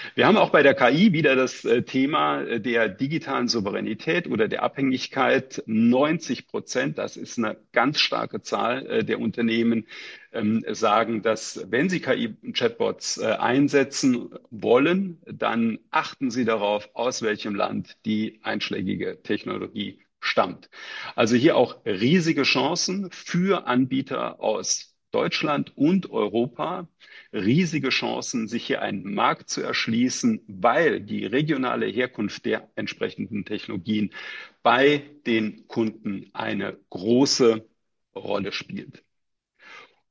Mitschnitte der Pressekonferenz
pressekonferenz-digital-office-2025-rolle-digitale-souveraenitaet-bei-nutzung-ki-chatbots.mp3